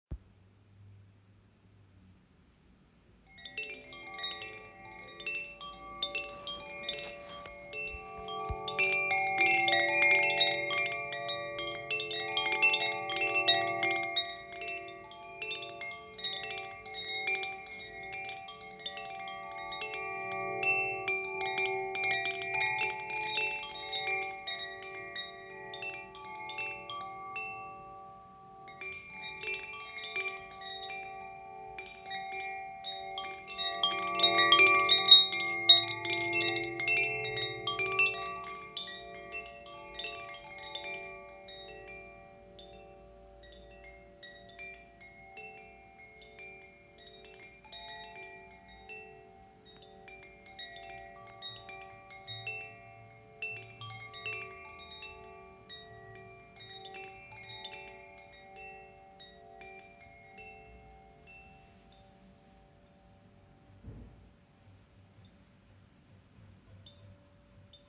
Handgefertigtes Windspiel aus Aluminium, Stahl und Buchenholz
Tonfolge: F G A D in 432Hz
Acht Stäbe werden mit Silberschweissung gefasst, der Aluminiumkörper geformt wie eine Resonanzglocke – und zusammen erschaffen sie ein Instrument voller Klarheit, Tiefe und schwebender Obertöne.